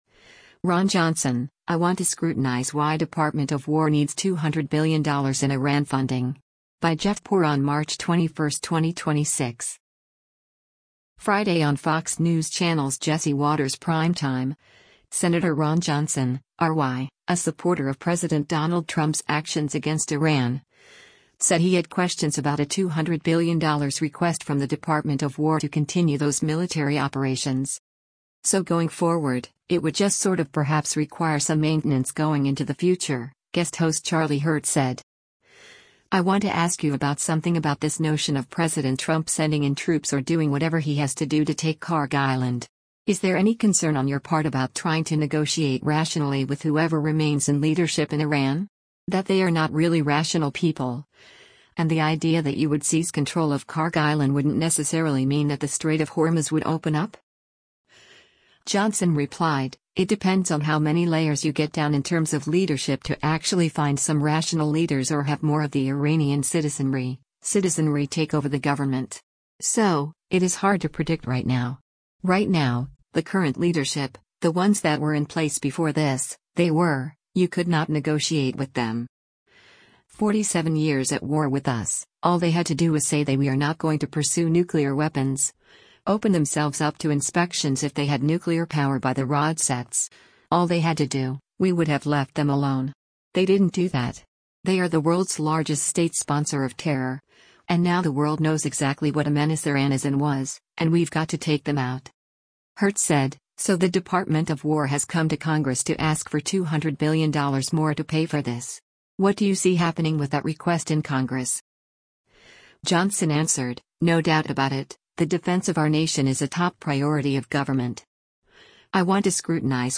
Friday on Fox News Channel’s “Jesse Watters Primetime,” Sen. Ron Johnson (R-WI), a supporter of President Donald Trump’s actions against Iran, said he had questions about a $200 billion request from the Department of War to continue those military operations.